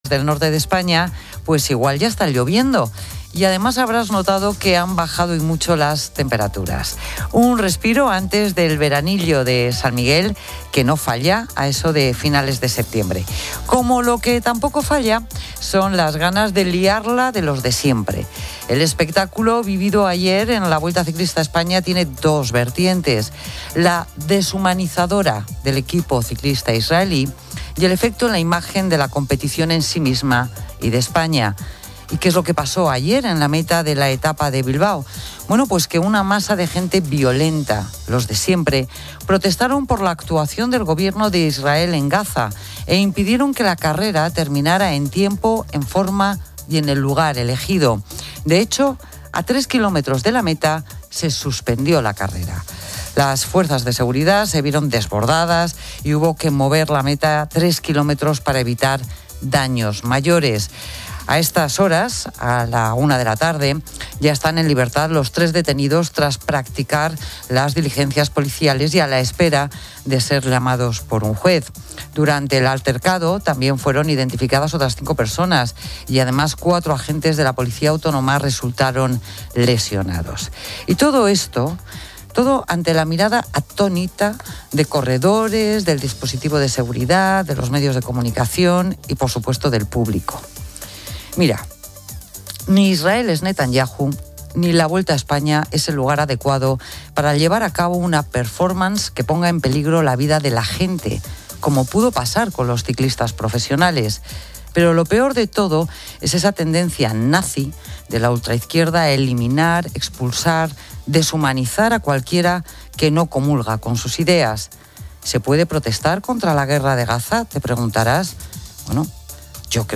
Se informa sobre una bajada de temperaturas y lluvias en el norte del país. Se detalla un incidente en la Vuelta Ciclista a España en Bilbao, donde protestas relacionadas con el conflicto palestino-israelí interrumpieron la carrera, resultando en detenciones y críticas a la violencia. La locutora también analiza el ascenso político de Bildu y sus métodos.